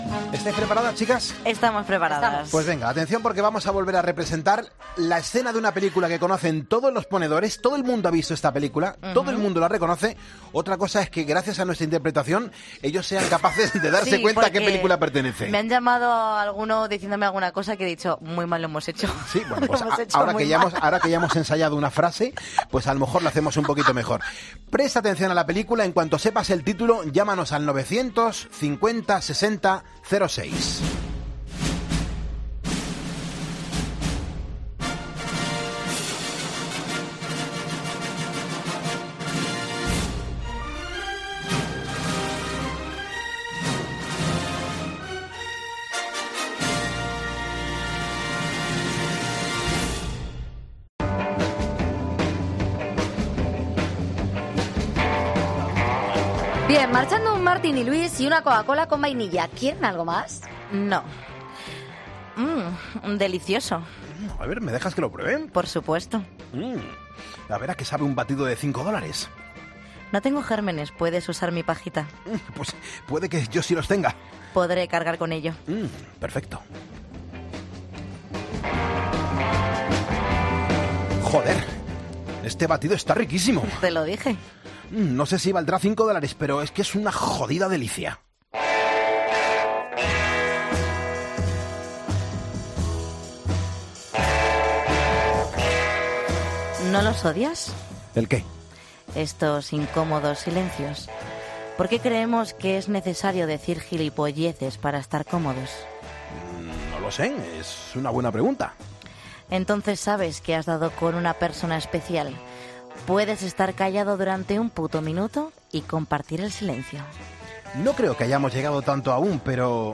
No somos actores de doblaje, solo nos lo pasamos bien y a los ponedores os regalamos unas risas.
Esta vez hemos interpretado una de las escenas más mitícas de Pulp Fiction.